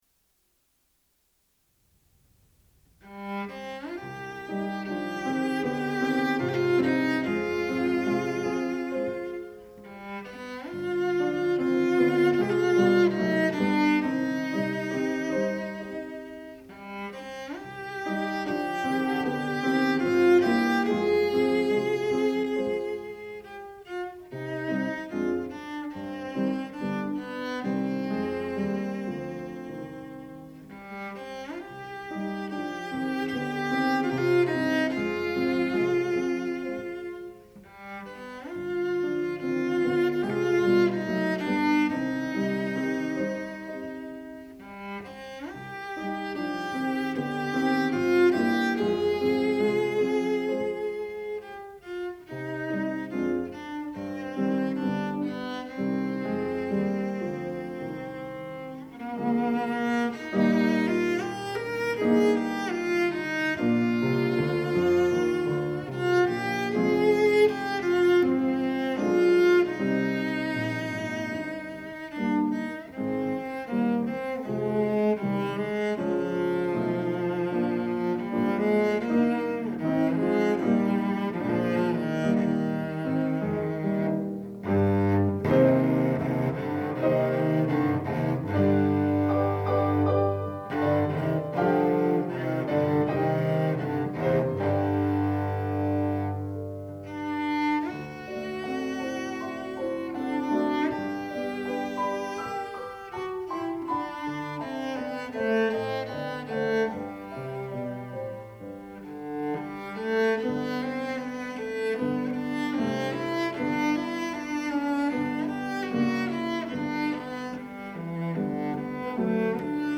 Jewish-inspired music for cello and piano
cello